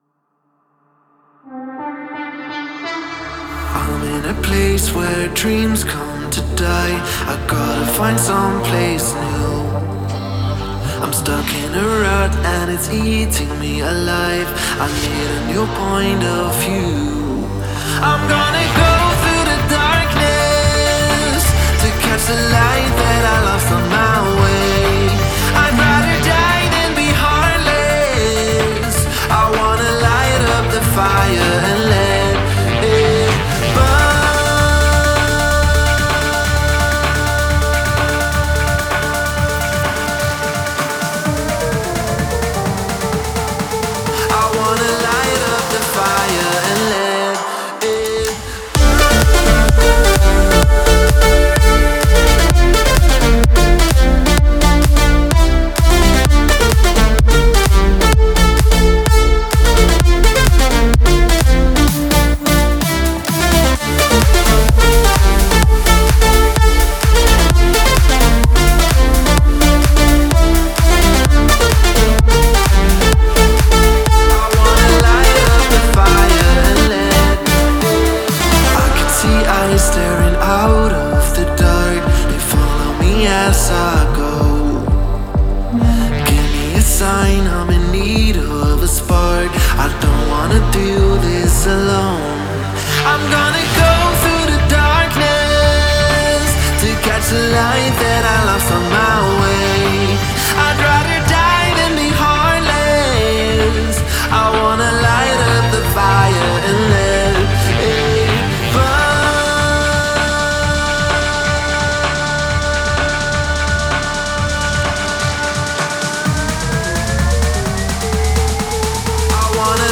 это динамичная электронная танцевальная композиция